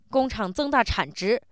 angry